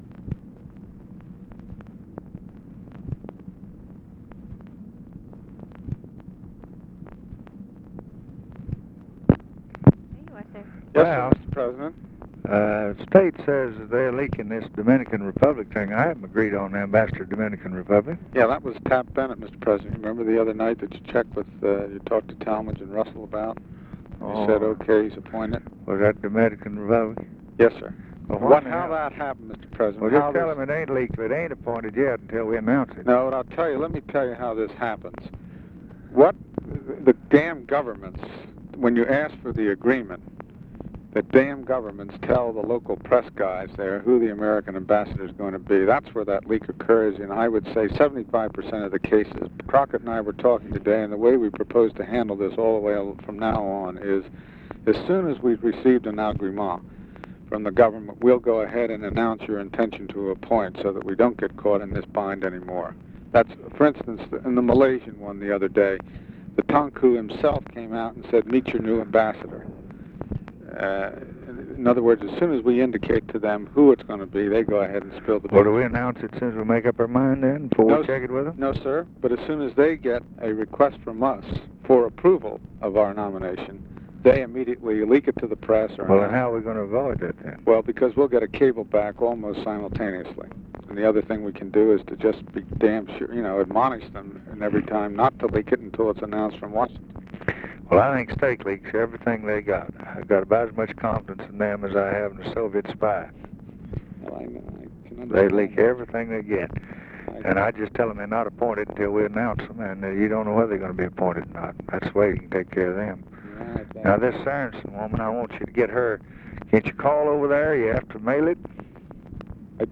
Conversation with RALPH DUNGAN, January 30, 1964
Secret White House Tapes